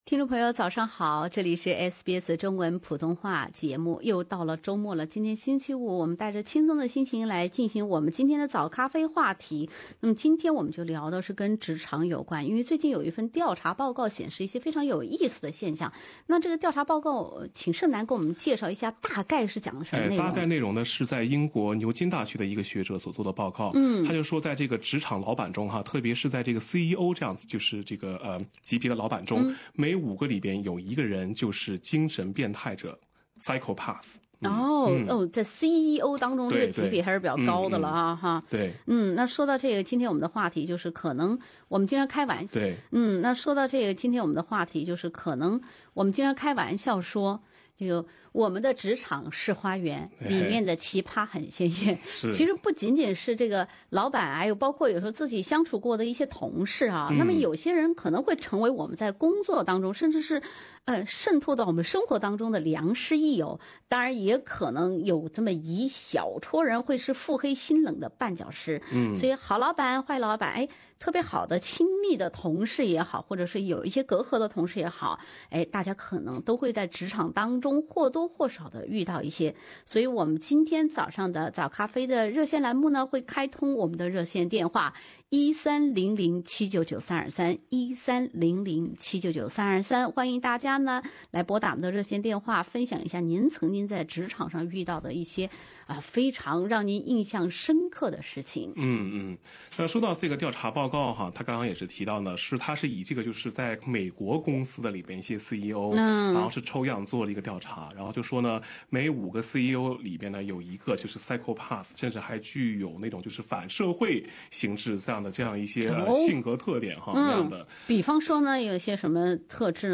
你所经历的老板们是工作中的良师益友，还是腹黑心冷的绊脚石？ 本期《早咖啡》节目，听众分享在工作中同老板们的那些事儿。